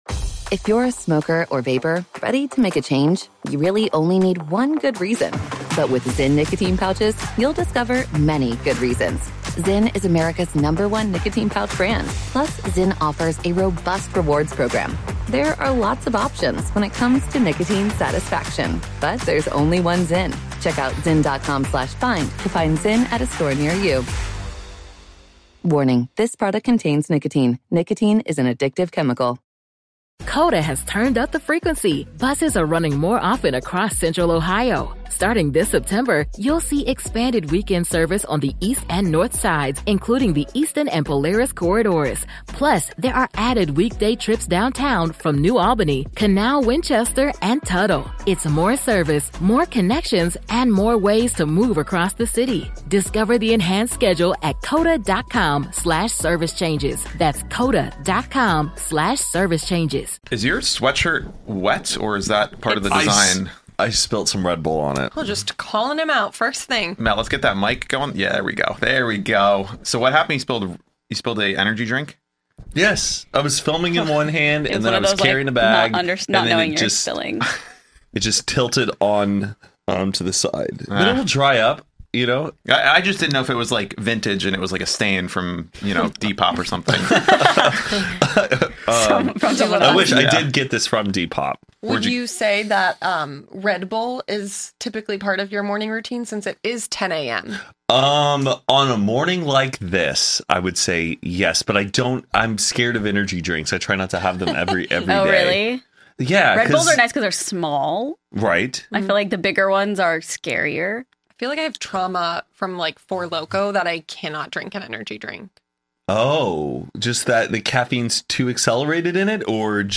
:-) The 4 hosts of Hoot & a Half and Only Friends have come together to create a supergroup show, where each week we will dive into and discuss, answer your questions, and more.